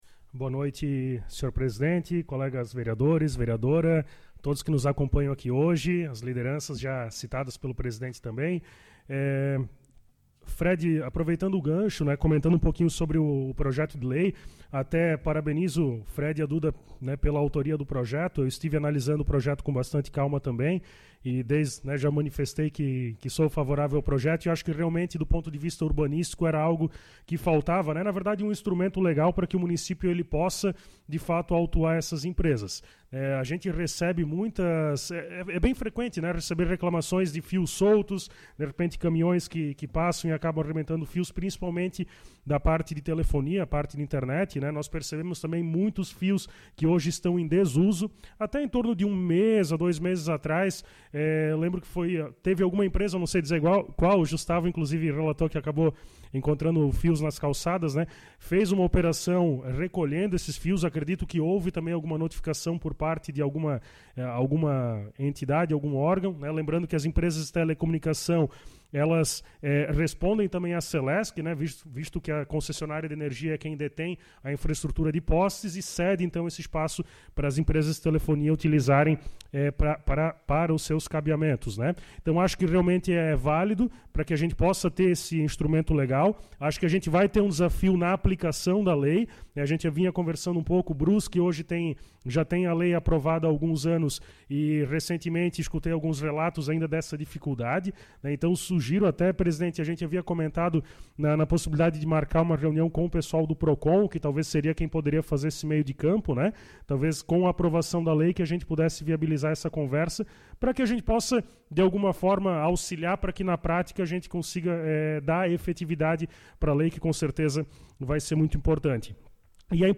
Na noite de terça-feira, 17, a Câmara Municipal de Guabiruba realizou sessão ordinária, sob a presidência do vereador Alexandre Felipe Pereira (Progressistas), com a presença dos demais oito parlamentares que compõem a atual legislatura.